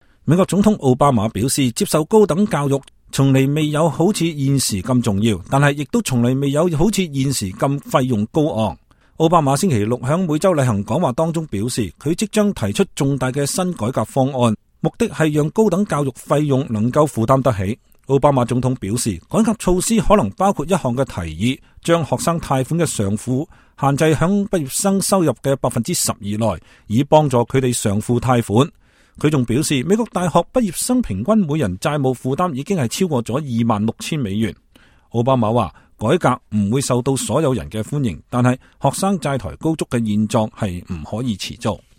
美國總統奧巴馬每週例行講話